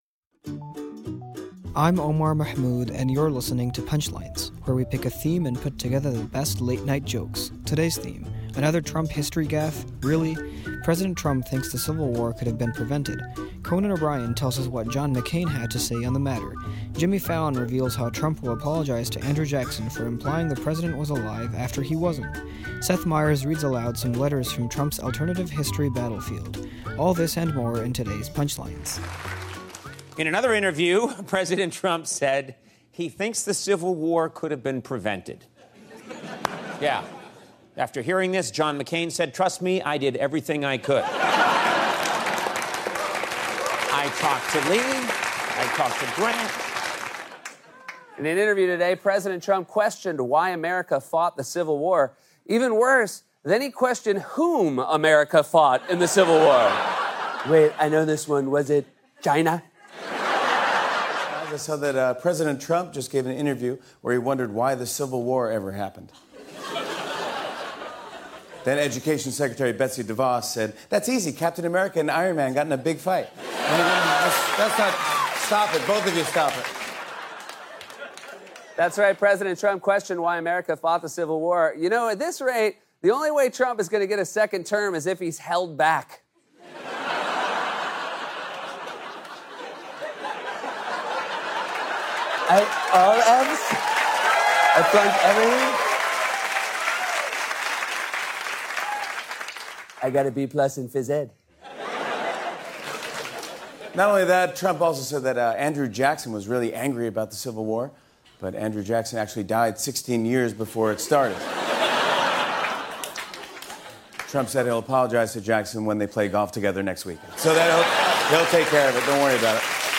The late-night comics talk Andrew Jackson, the Civil War and our blunderer in chief.